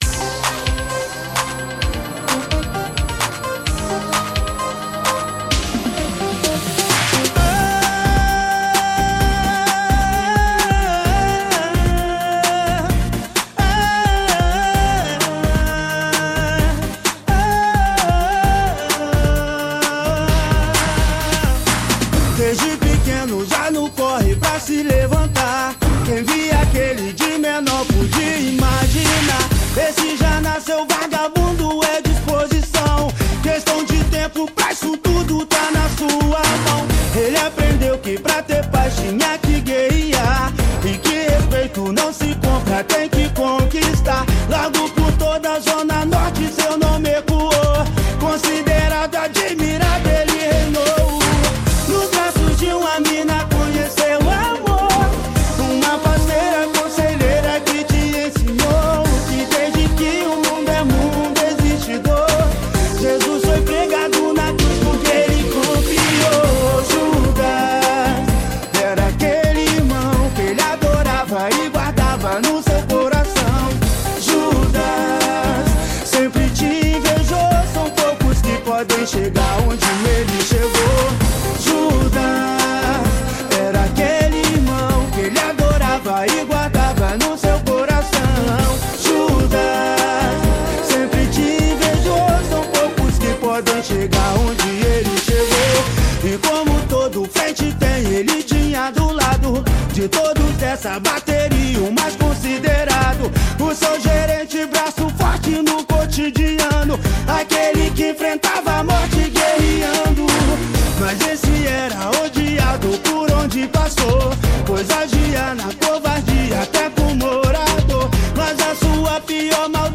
2025-01-27 23:02:58 Gênero: Funk Views